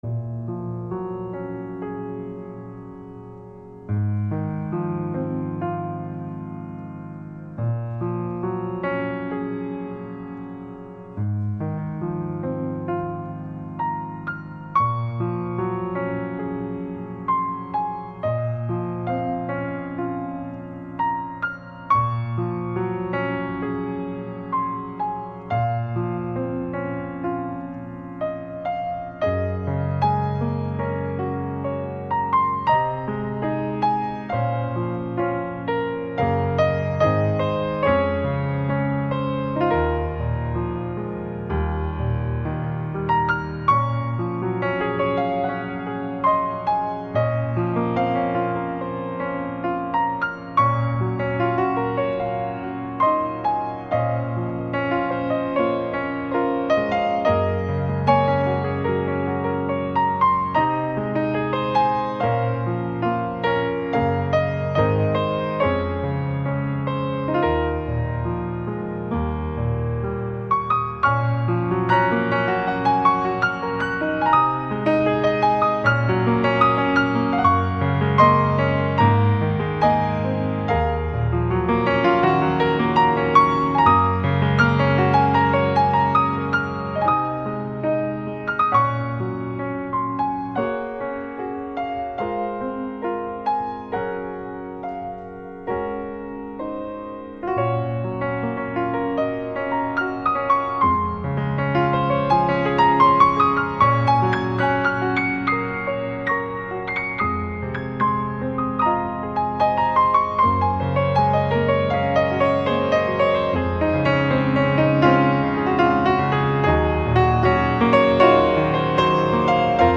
并且回到亲切的钢琴独奏从而表现出一种释然的情怀。